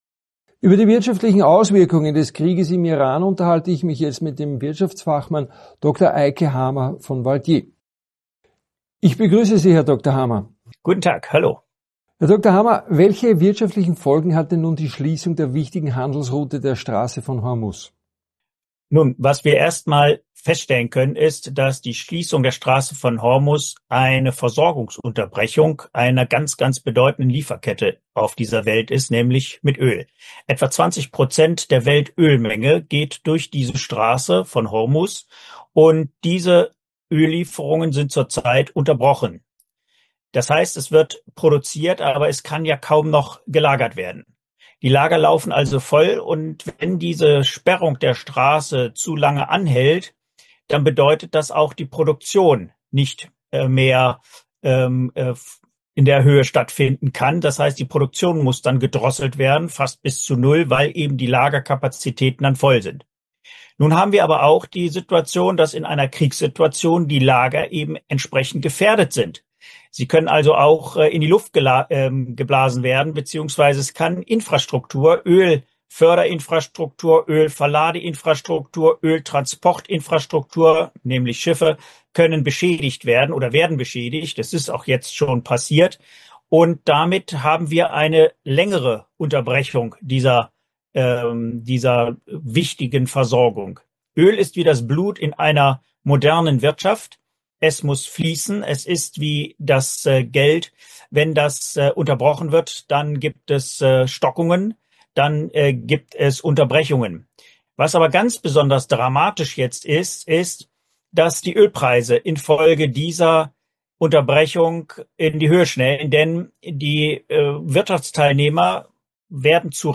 im exklusiven Interview mit AUF1.